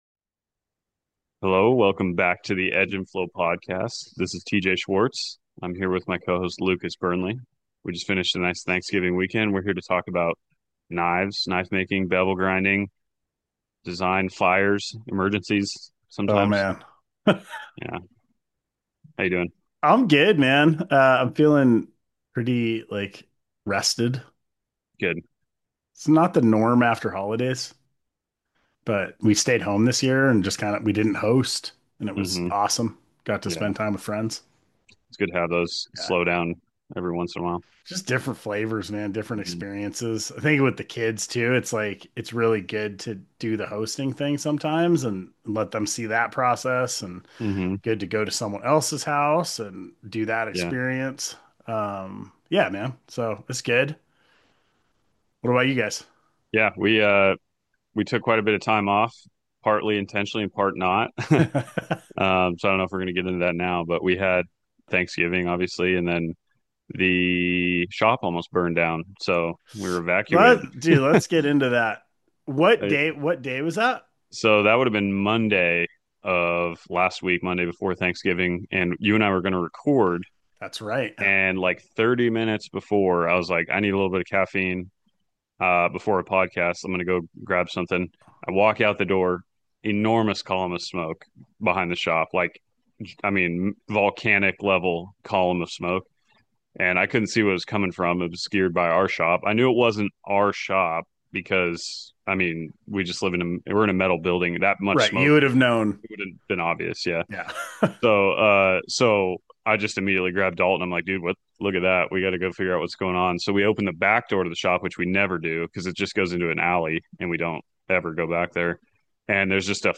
Free flowing, unscripted conversations unfold as we endeavor to explore the knife industry, craftsmanship, business, creativity, and beyond.